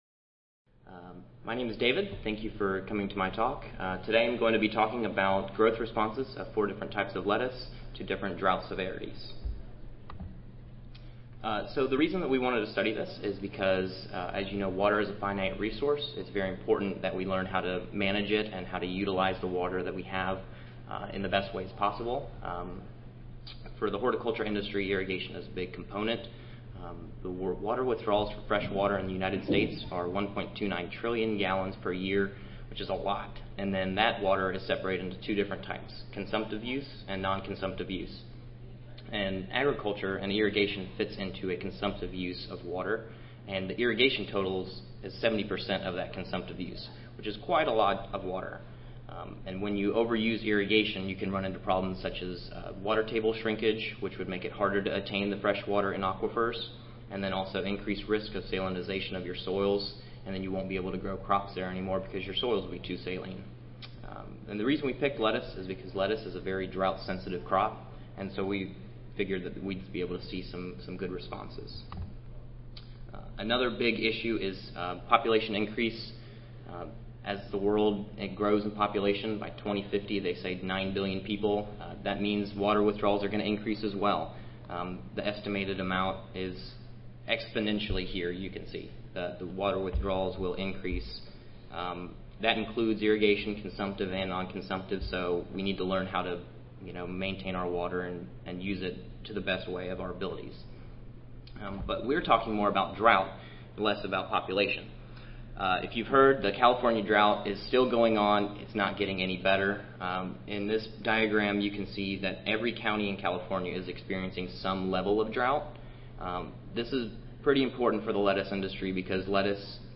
2015 ASHS Annual Conference: Growth Chambers and Controlled Environments 1 (Oral)
Recorded Presentation